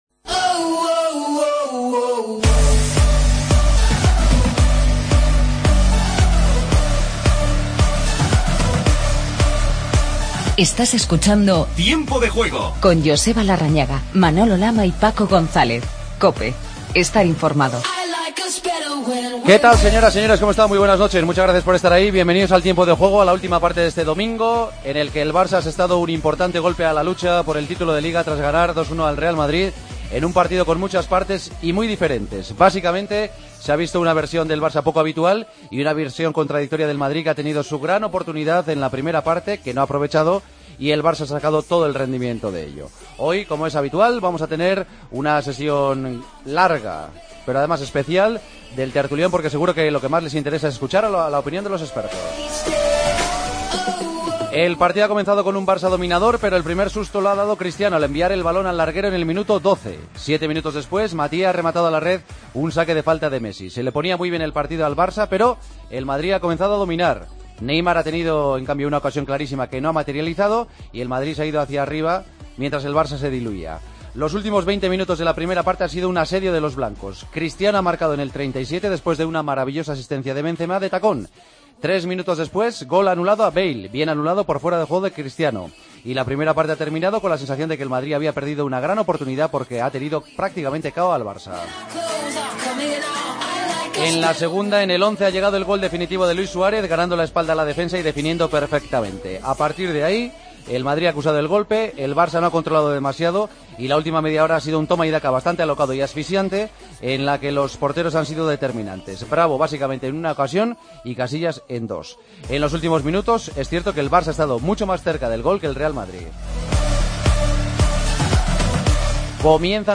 El Barcelona, lider con cuatro puntos de ventaja sobre el Real Madrid, al que ganó 2-1. Entrevista a Rakitic y escuchamos a Pepe y Marcelo.